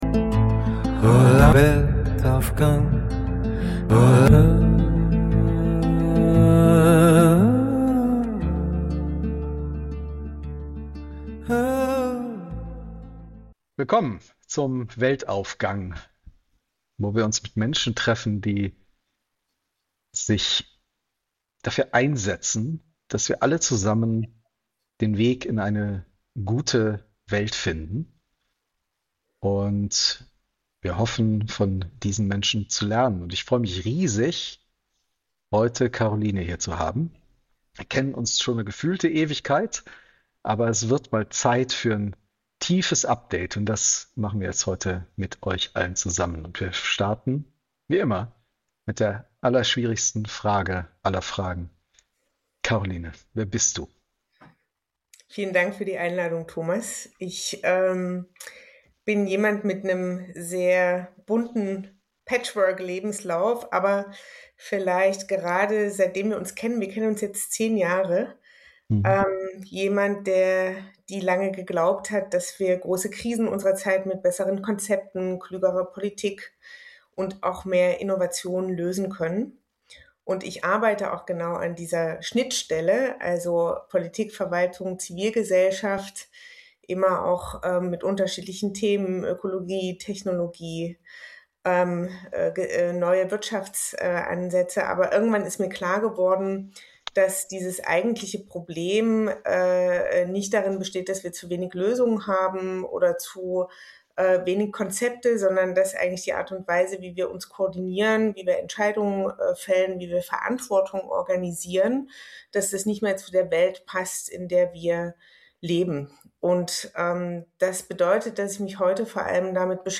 In diesem tiefgründigen Gespräch